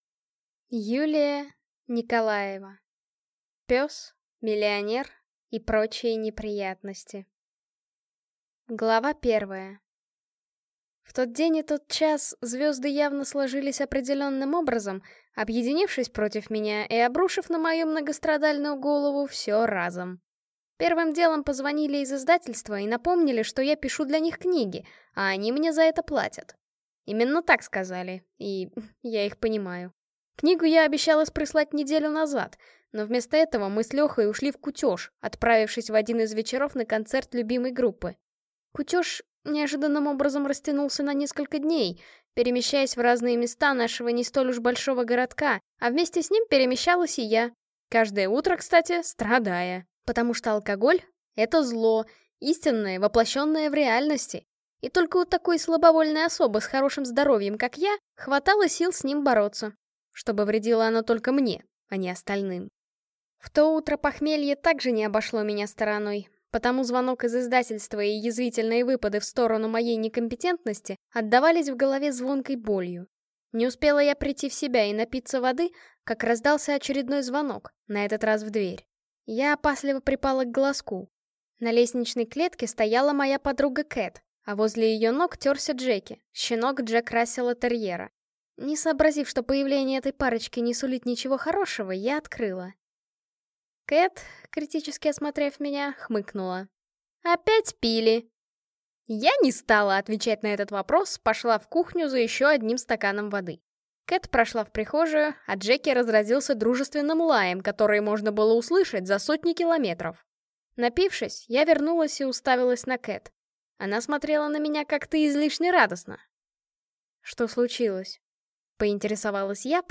Аудиокнига Пес, миллионер и прочие неприятности | Библиотека аудиокниг